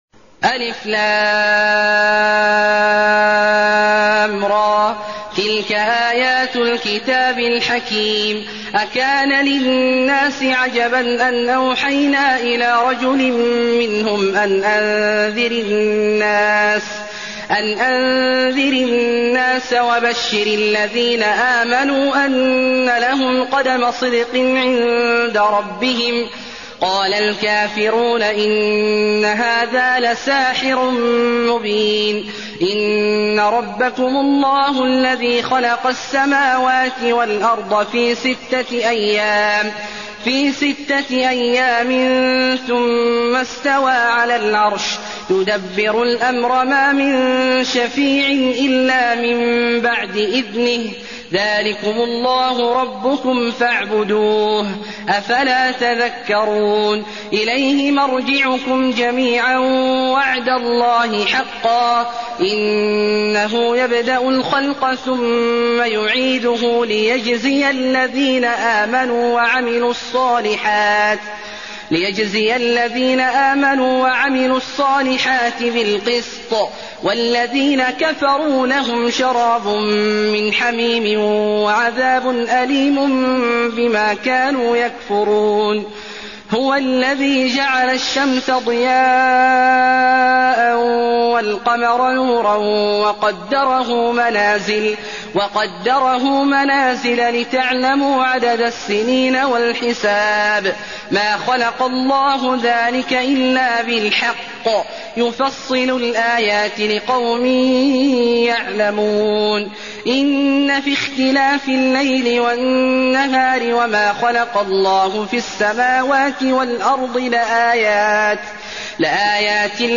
المكان: المسجد النبوي يونس